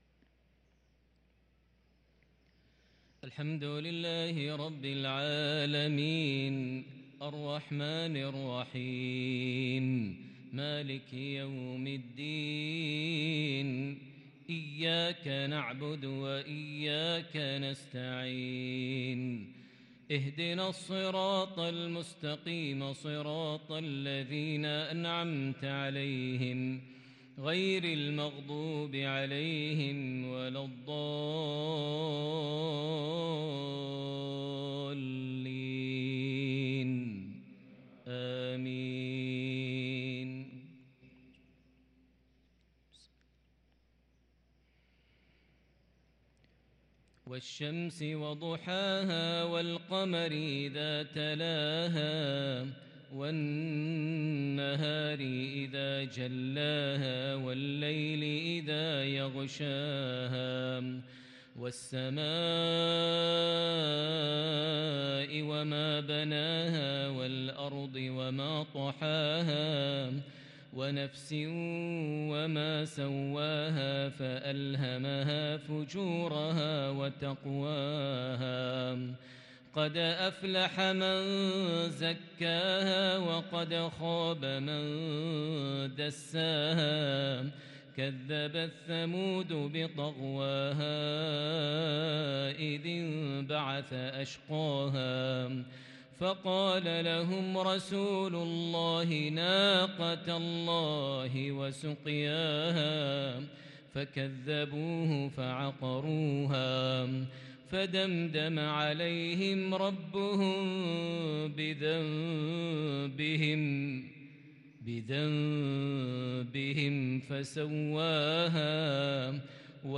صلاة المغرب للقارئ ماهر المعيقلي 25 جمادي الأول 1444 هـ
تِلَاوَات الْحَرَمَيْن .